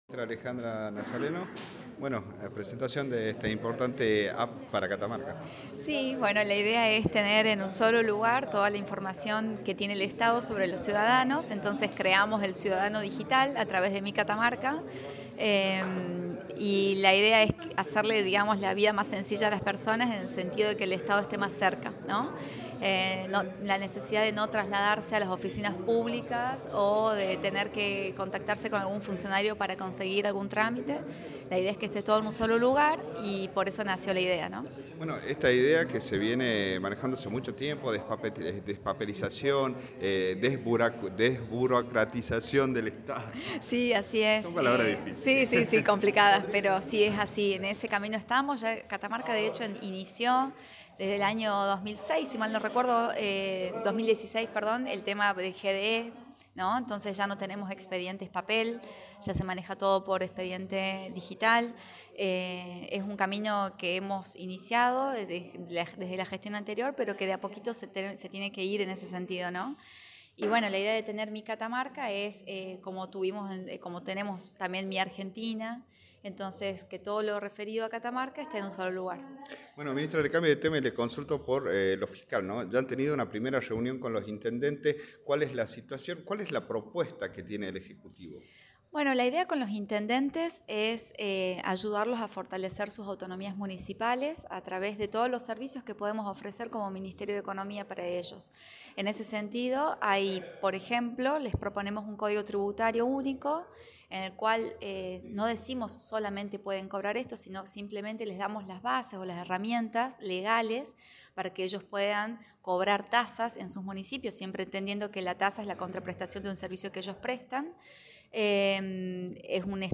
Entrevistas City